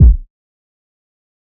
Portland Kick 1.wav